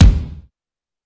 描述：循环节拍鼓
声道立体声